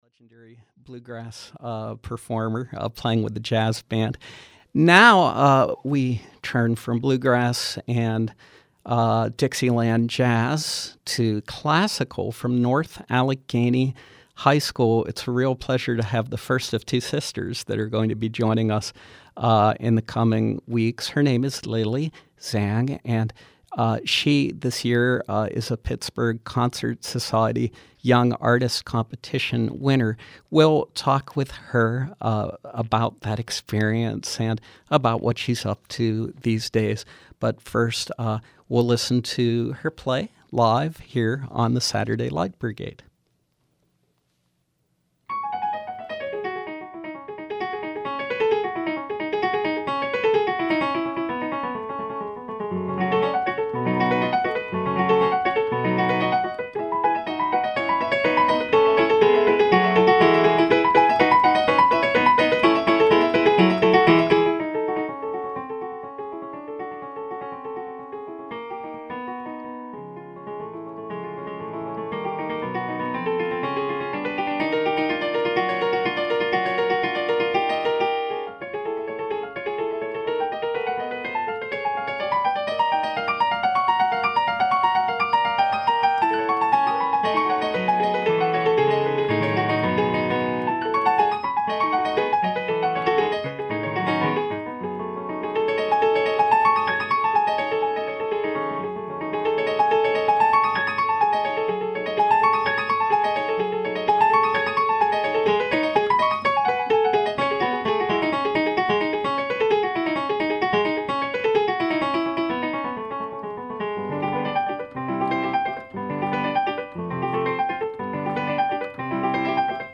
The Pittsburgh Concert Society’s Young Artists Competition provides a recital opportunity and support to talented classical musicians.
who performed selections on piano.